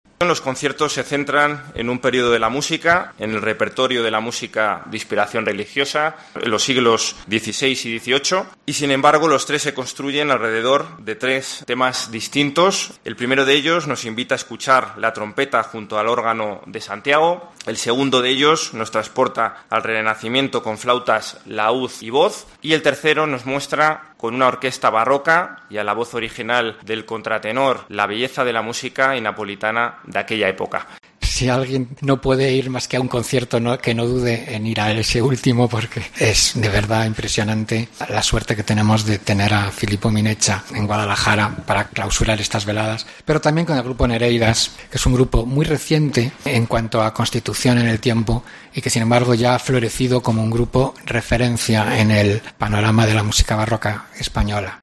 Armengol Engonga, Concejal de Cultura